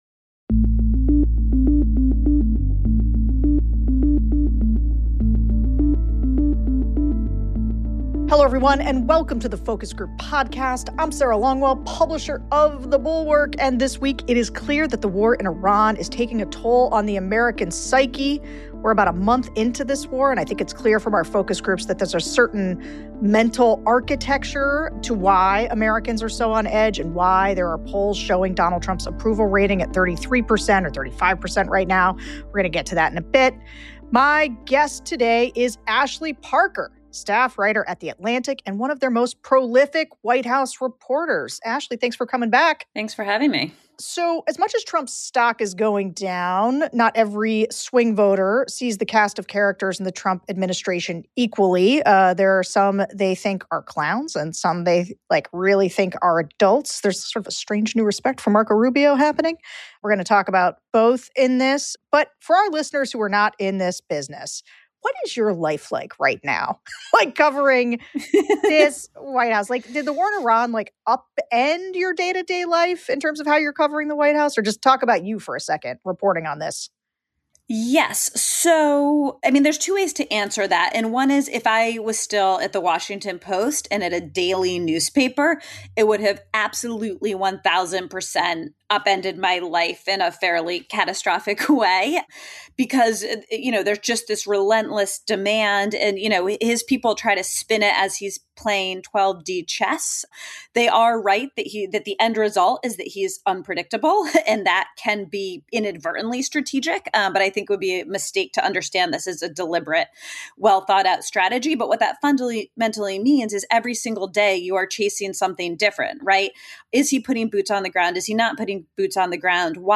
Atlantic staff writer Ashley Parker returns to the show.